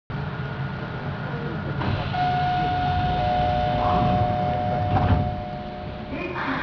・9000形ドアチャイム
【西側】閉扉時（6秒：38.2KB）
開扉時は２回、閉扉時は１回流れます。西側は２打点、東側は３打点となっています。